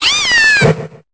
Cri de Flamiaou dans Pokémon Épée et Bouclier.